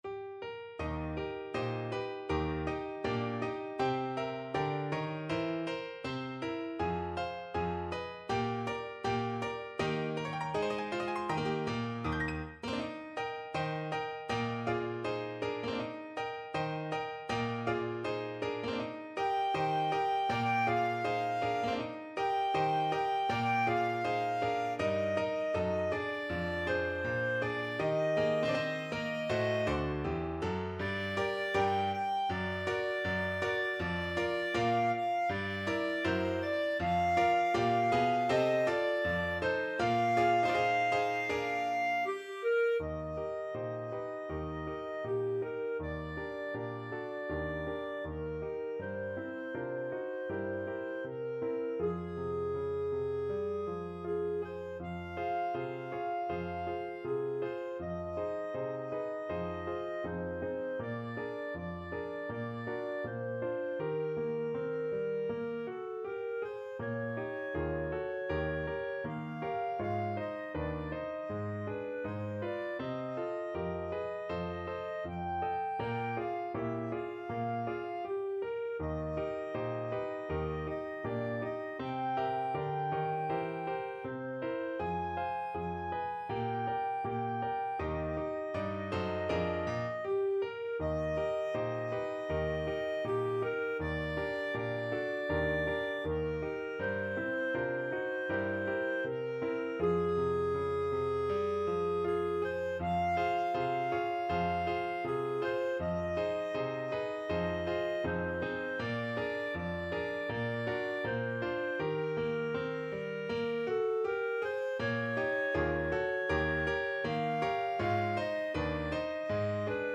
2/2 (View more 2/2 Music)
Moderato =80
Pop (View more Pop Clarinet Music)